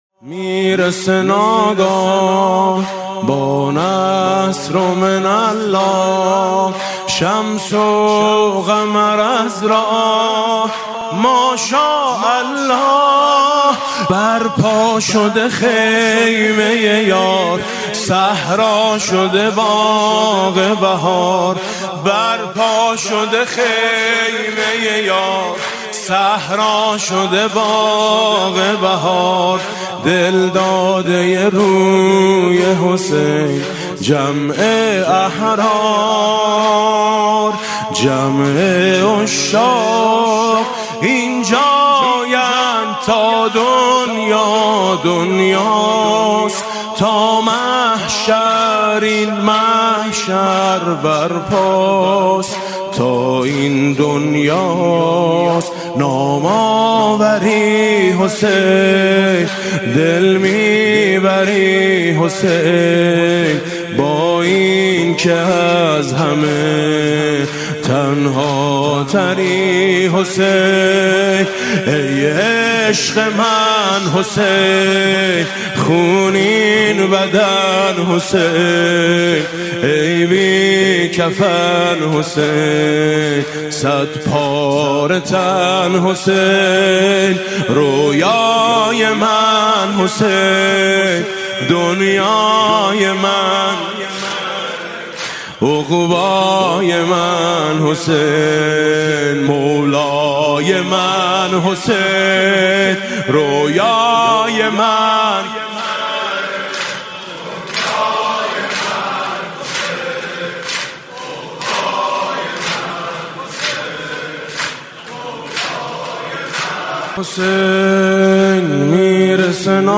مداحی شب دوم محرم